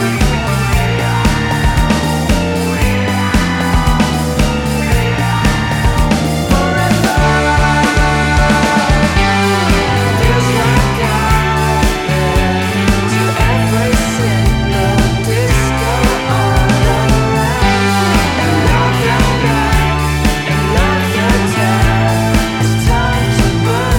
no Backing Vocals Indie / Alternative 3:49 Buy £1.50